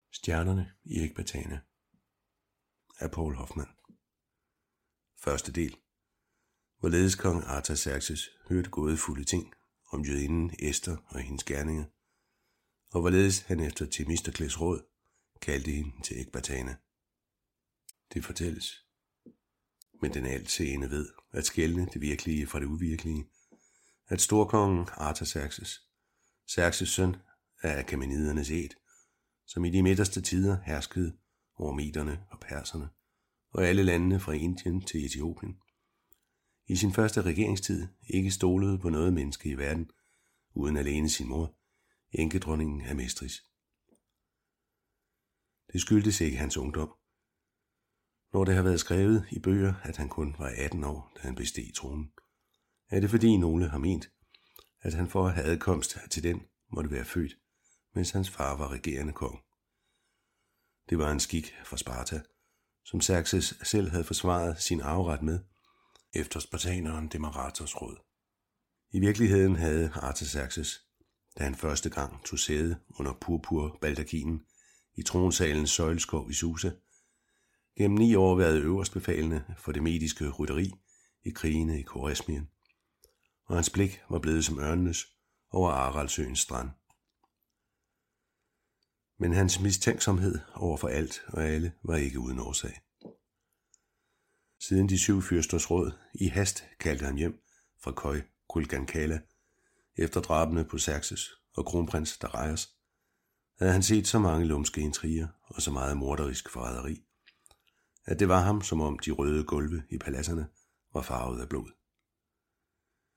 Hør et uddrag af Stjernerne i Ekbatana Stjernerne i Ekbatana Ester II Format MP3 Forfatter Poul Hoffmann Lydbog 149,95 kr.